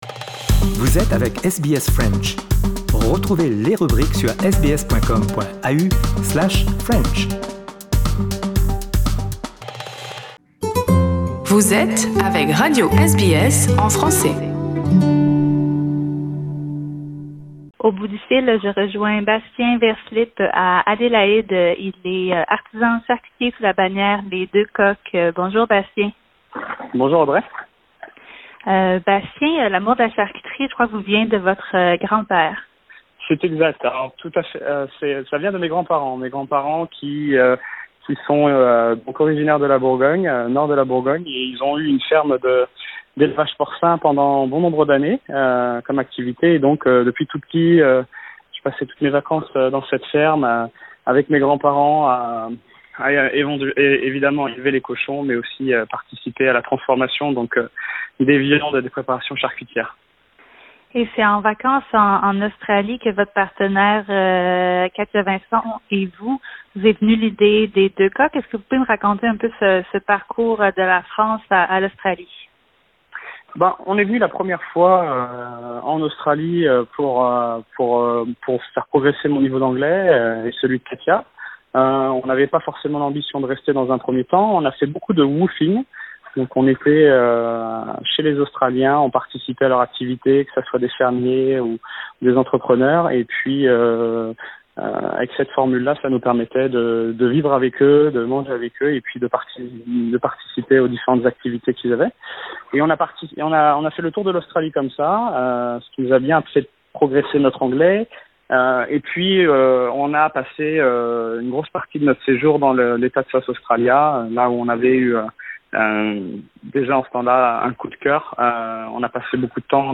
Pour faire le plein de pâté en croûte, saucisson et tapenade en Australie-Méridionale, il faut visiter Les Deux Coqs au Adélaïde Central Market. Entretien